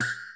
Index of /90_sSampleCDs/Zero-G Groove Construction (1993)/Drum kits/Euro techno/Hits & FX